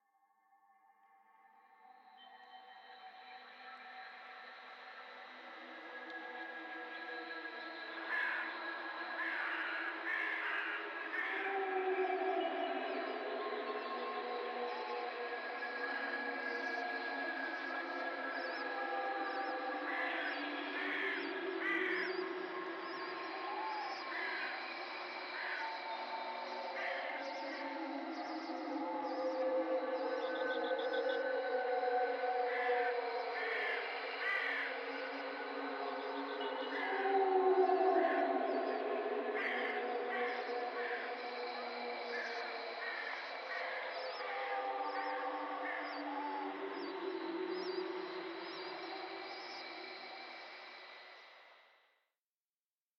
02_进门过道.ogg